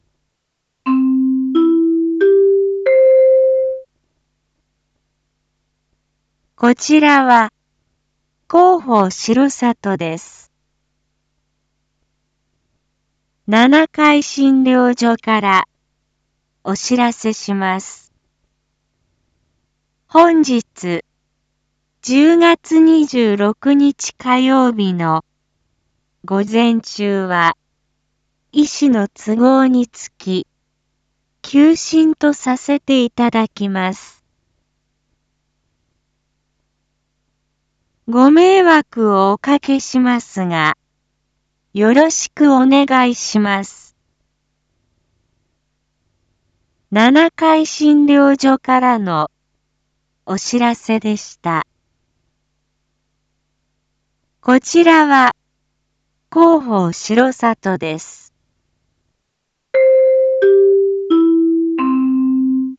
一般放送情報
Back Home 一般放送情報 音声放送 再生 一般放送情報 登録日時：2021-10-26 07:01:05 タイトル：R3.10.26 インフォメーション：こちらは広報しろさとです 七会診療所からお知らせします 本日１０月２６日火曜日の午前中は、医師の都合につき、休診とさせていただきます。